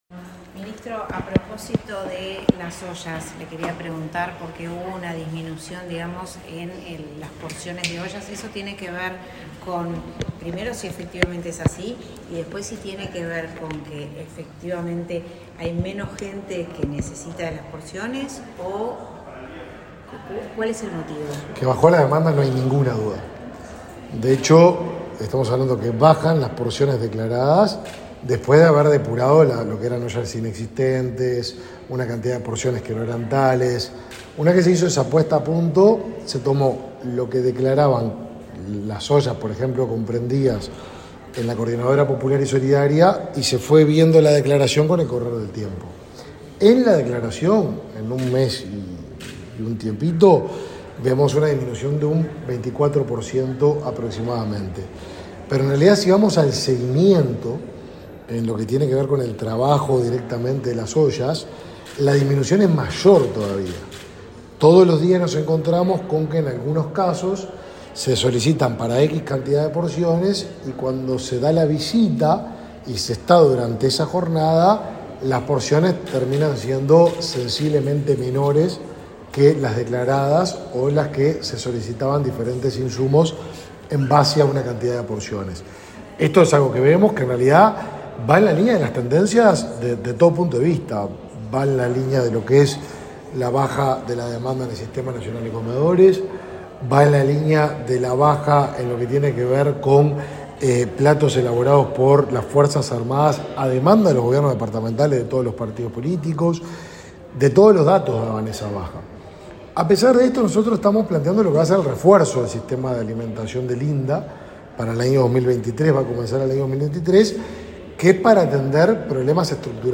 Declaraciones del ministro de Desarrollo Social, Martín Lema
Este martes 29 en el Palacio Legislativo, el ministro de Desarrollo Social, Martín Lema, participó de la sesión de la Junta Nacional de Cuidados.
Luego, dialogó con la prensa.